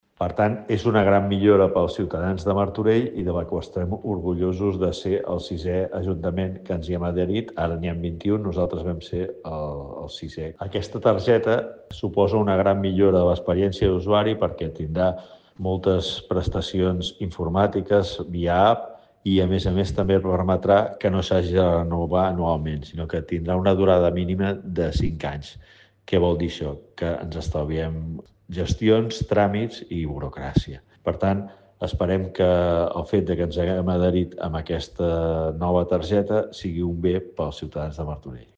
Lluís Sagarra, regidor de Mobilitat